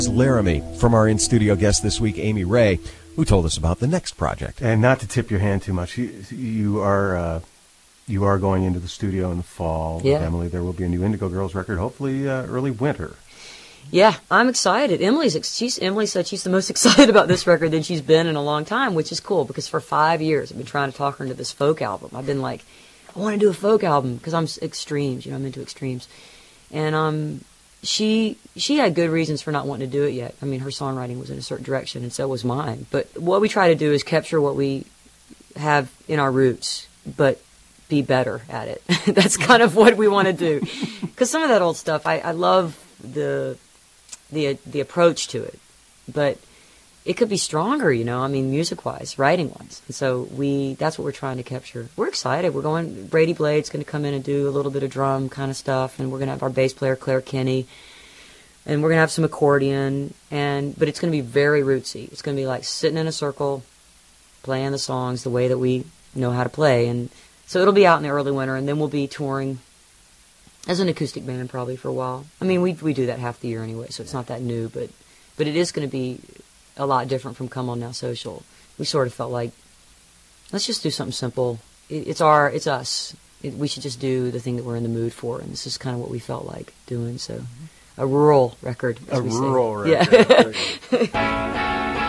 lifeblood: bootlegs: 2001-09-10: acoustic cafe radio show (amy ray)
09. interview (1:32)